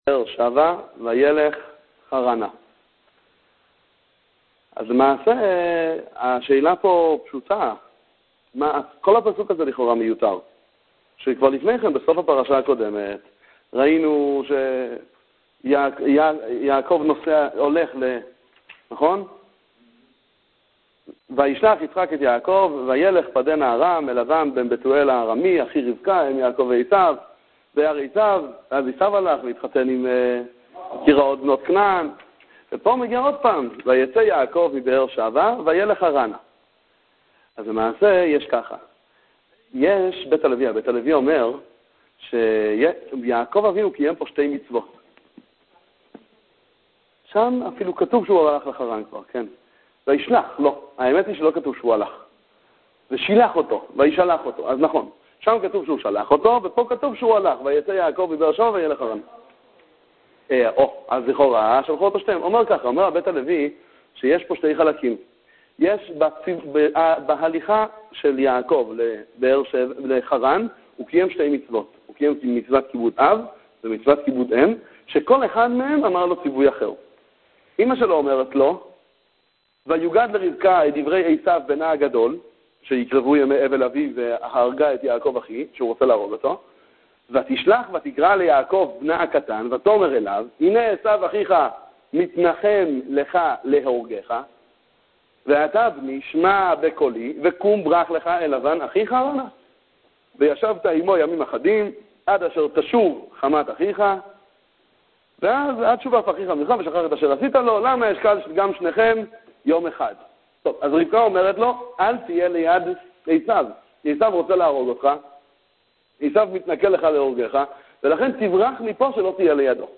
שיעורי תורה על פרשת השבוע, דבר תורה לשולחן שבת